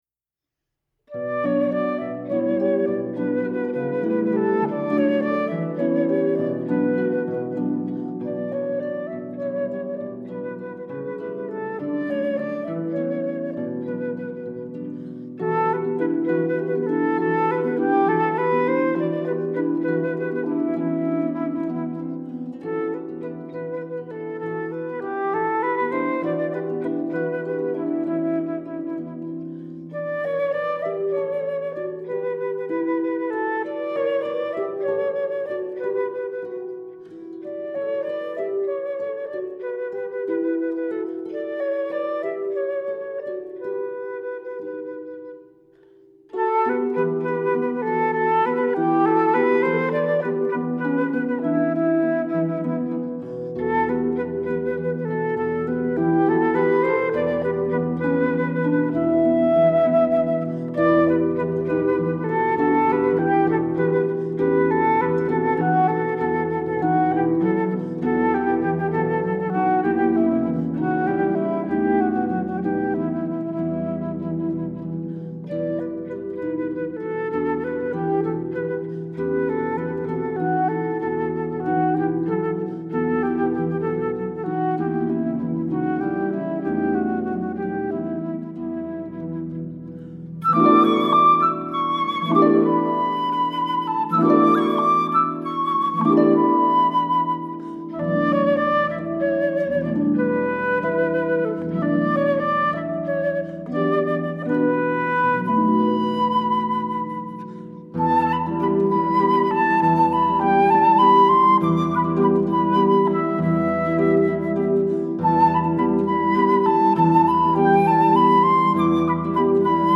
traditional Armenian folk songs for flute and pedal harp